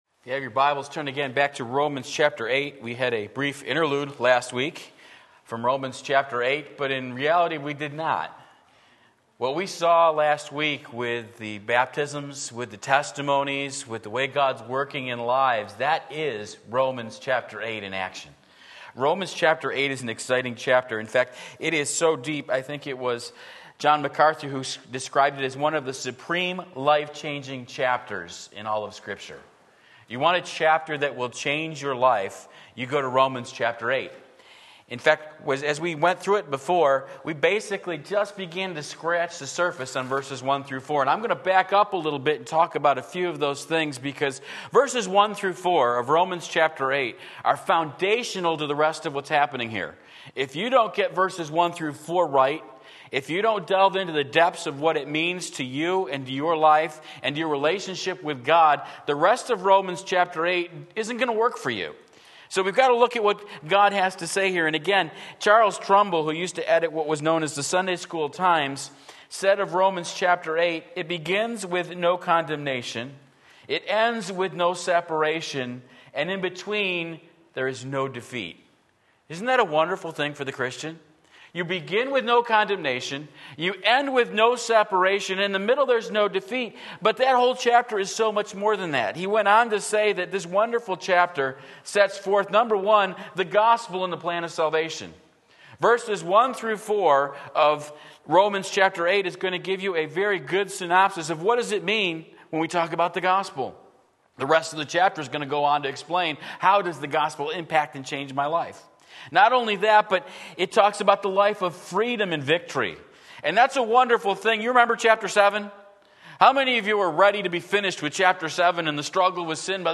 Sermon Link
A Matter of the Mind Romans 8:5-6 Sunday Morning Service